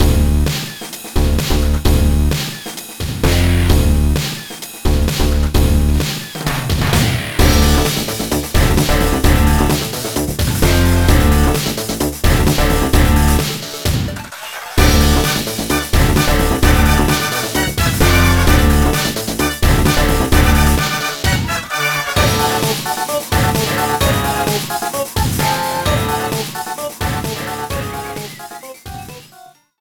Slideshow music